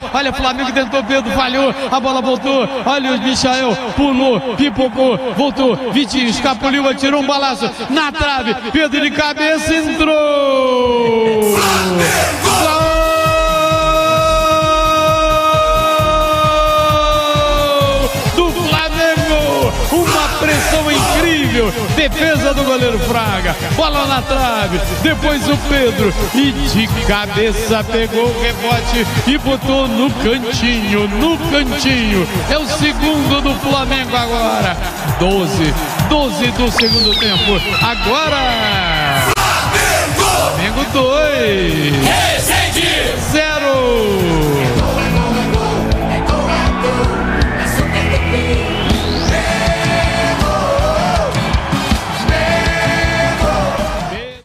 Ouça os gols da vitória do Flamengo sobre o Resende com a narração de José Carlos Araújo